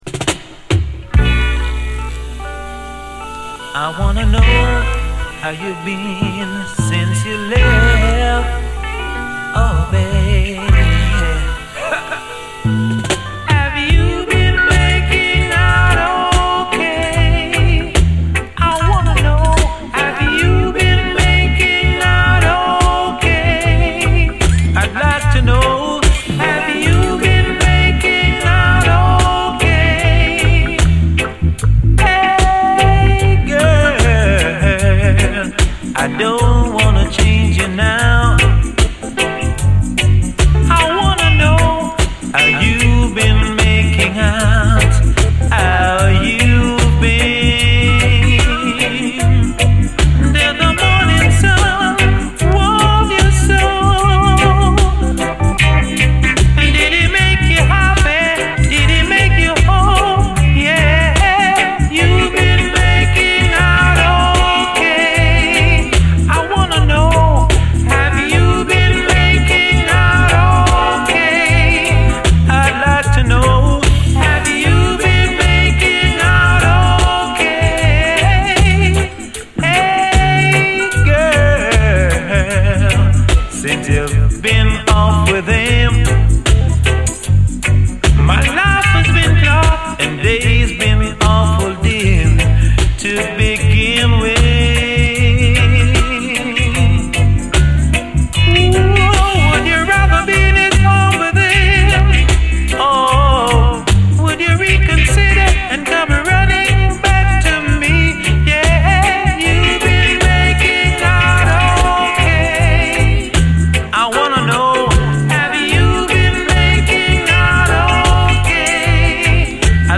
REGGAE
コク深くトロットロにトロける極上トラック！